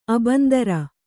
♪ abandara